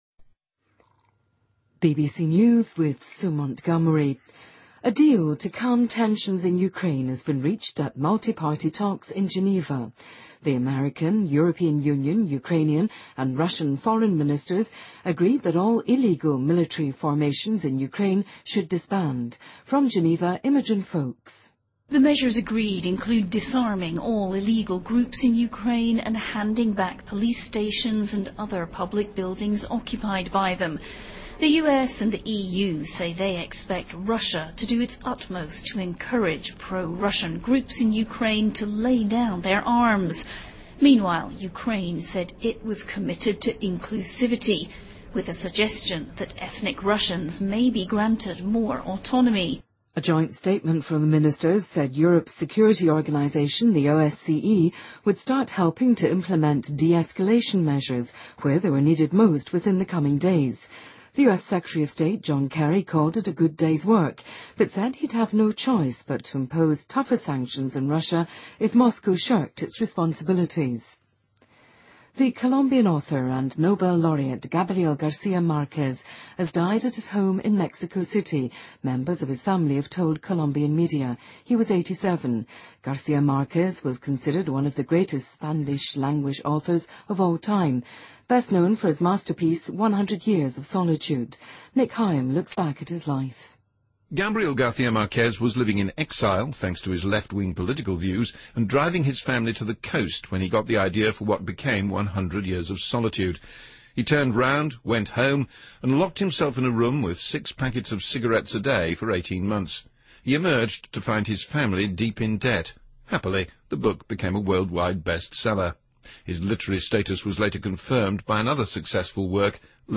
BBC news,美国决定采取措施解除对伊朗资金的冻结